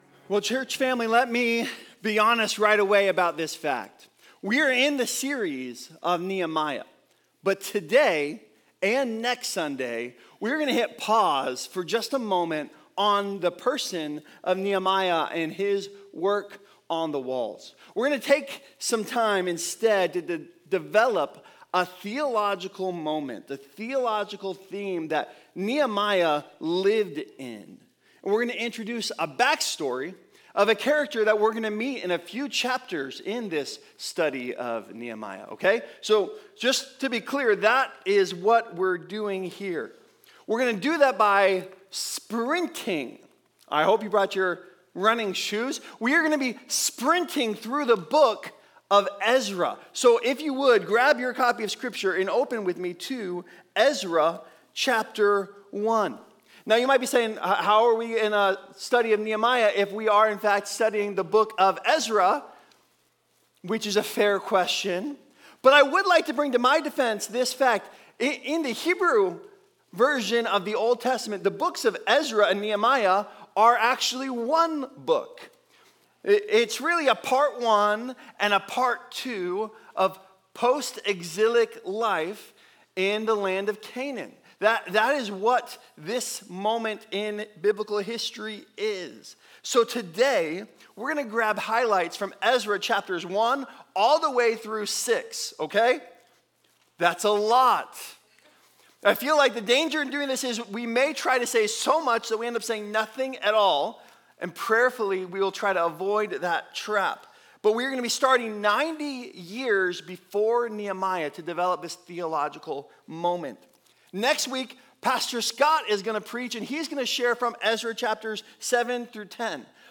Quickly and easily listen to HP Campus Sermons for free!